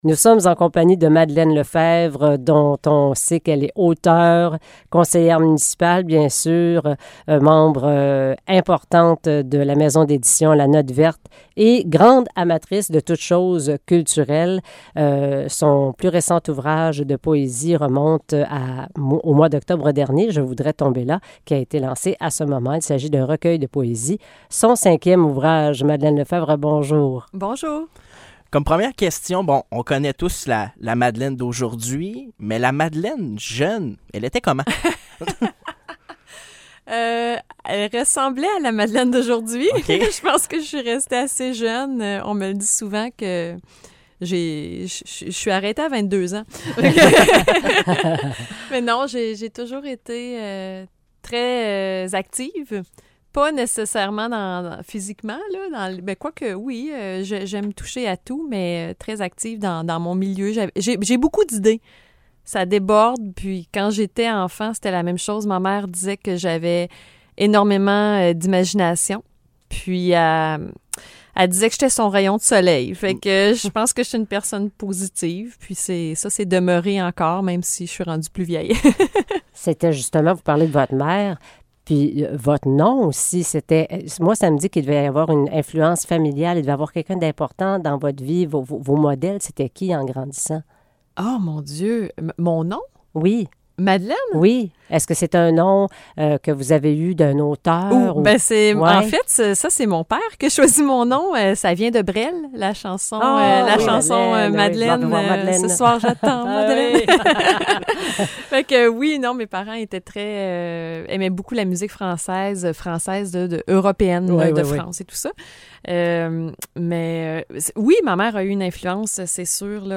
De passage dans nos studios, elle nous partage ses passions et son parcours.